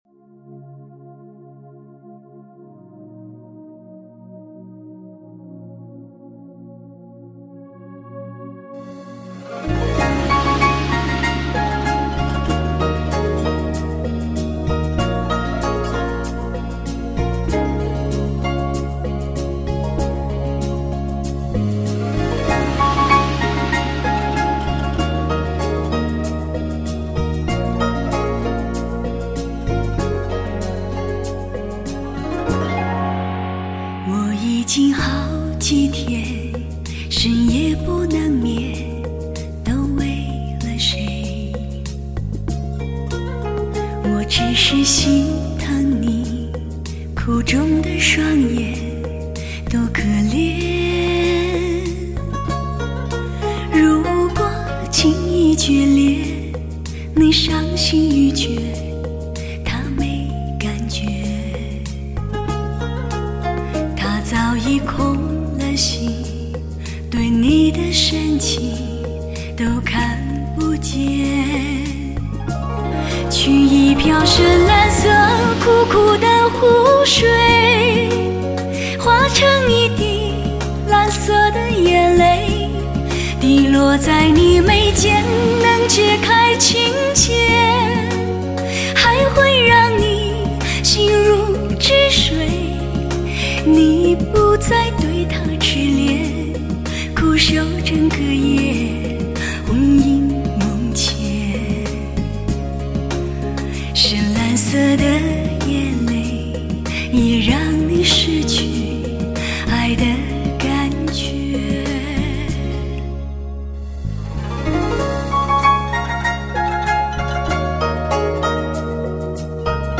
震天动地的强劲动态  举座皆惊的靓绝旋律
至靓人声  发烧制作  经典好歌  聆听极品
试听为低品质128k/mp3，下载为320k/mp3